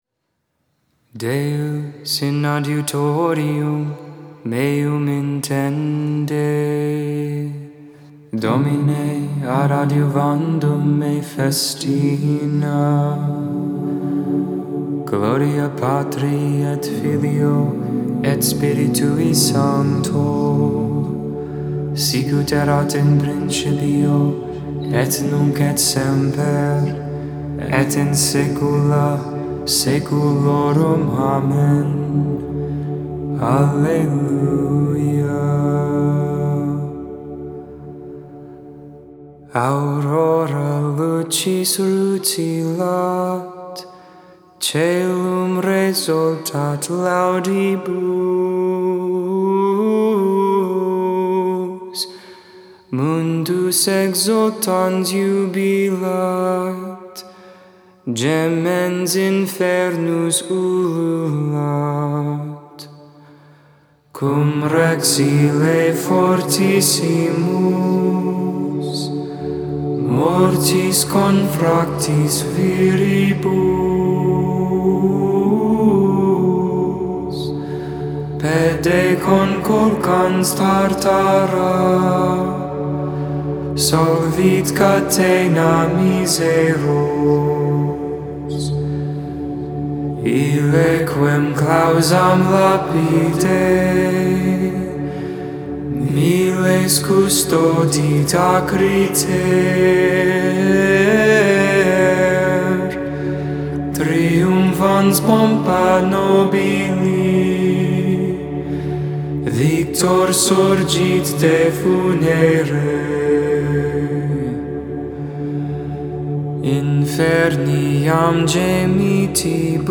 Deus in Adjutorium Hymn: "Aurora Lucis Rutilat" (chorus) Psalm 87 Canticle: Isaiah 40v10-17 Psalm 99 Reading:Romans 8v10-11 Responsory: The Lord is risen from the tomb, alleluia, alleluia.